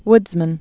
(wdzmən)